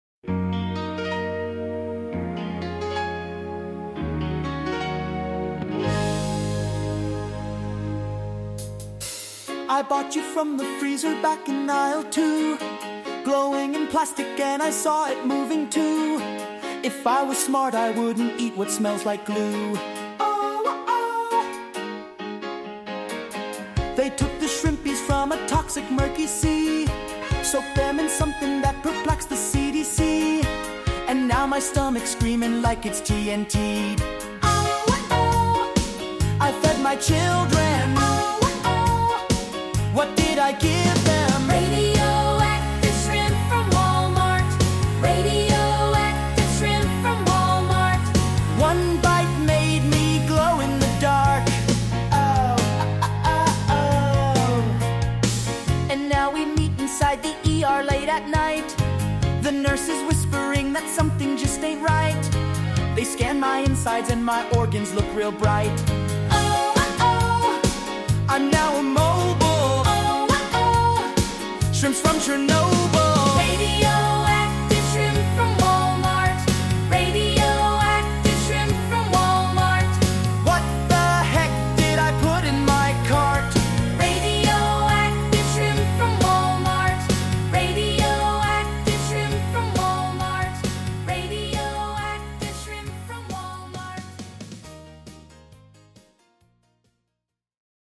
custom-parody-song-radioactive-shr.mp3